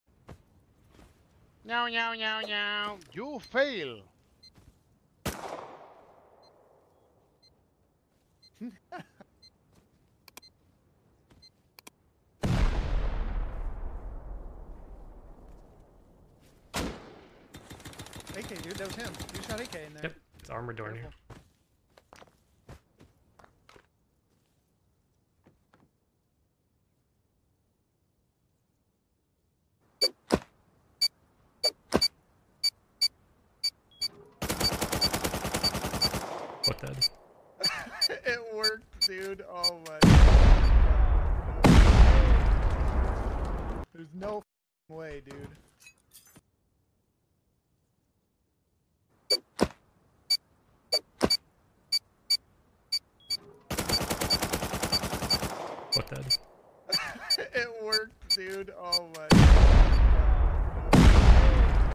He’s simulating the C4 sound sound effects free download